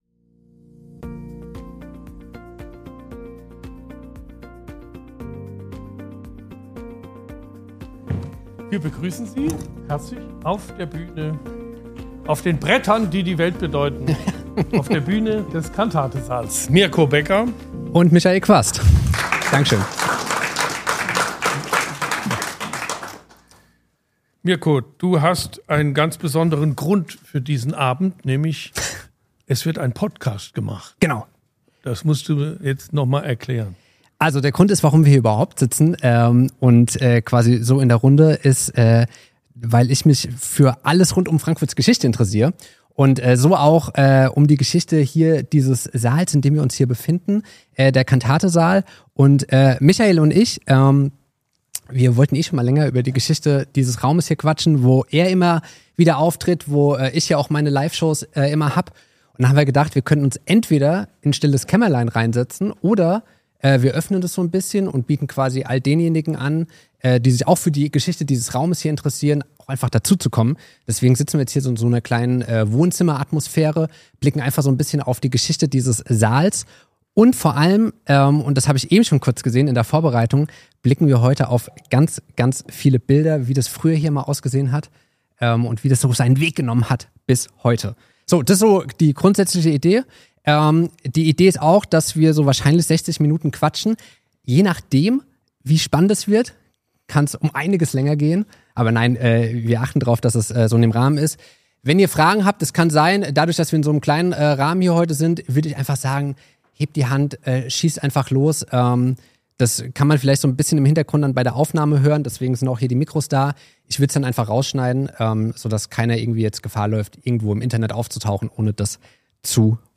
Beschreibung vor 5 Monaten In dieser Episode bin ich live vor Ort an der Volksbühne - genauer gesagt im Cantate-Saal.